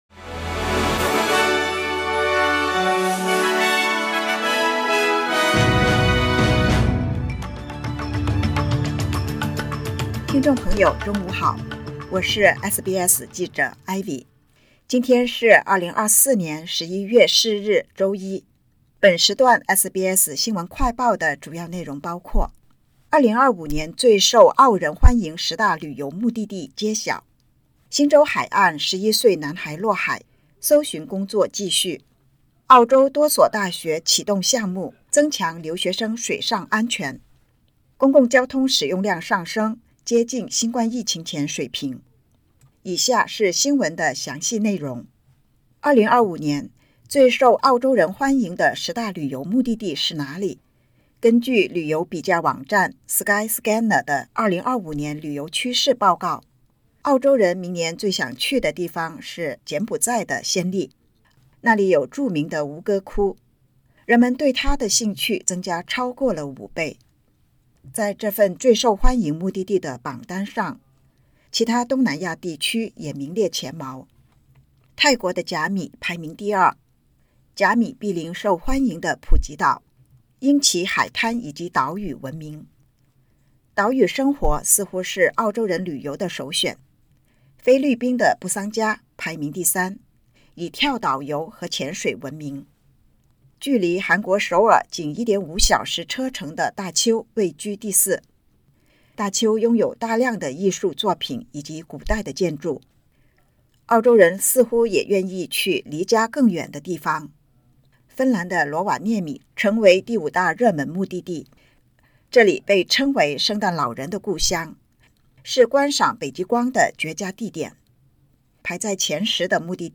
【SBS新闻快报】2025年最受澳人欢迎十大旅游目的地揭晓